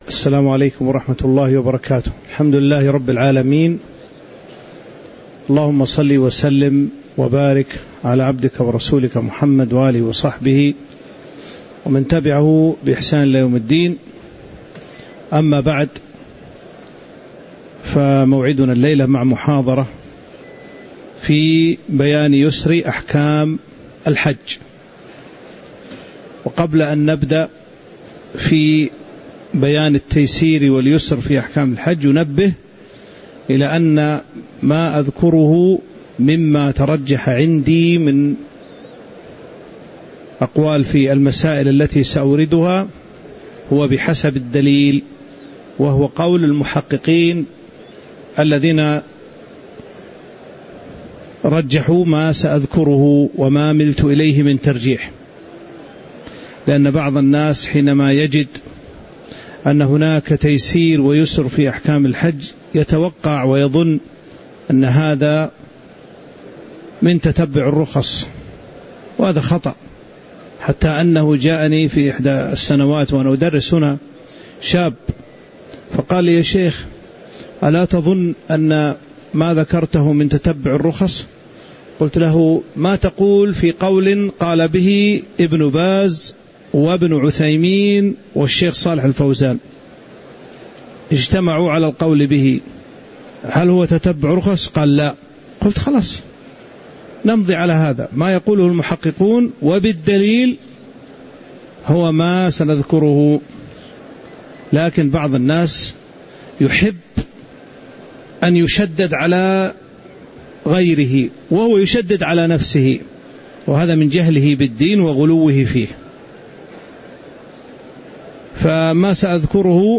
تاريخ النشر ٢٤ ذو القعدة ١٤٤٦ هـ المكان: المسجد النبوي الشيخ